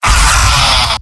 Demoman_mvm_m_painsevere04.mp3